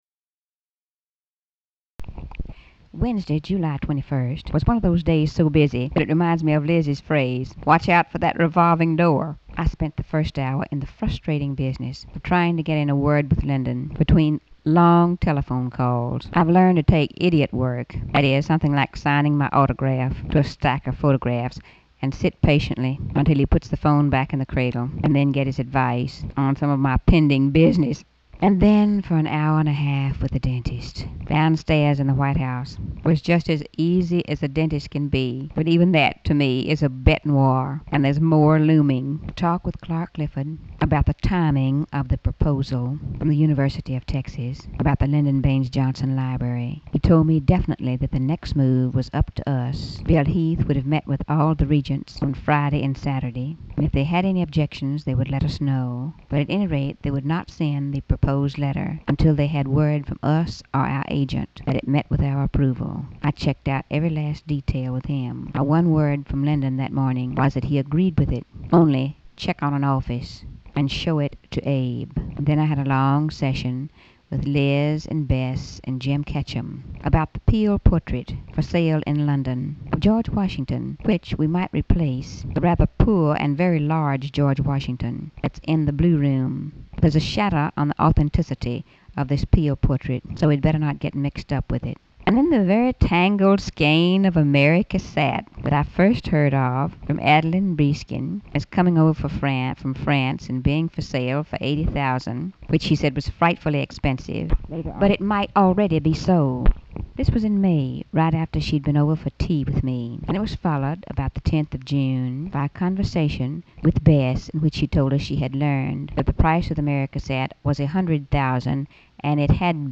Audio diary and annotated transcript, Lady Bird Johnson, 7/21/1965 (Wednesday) | Discover LBJ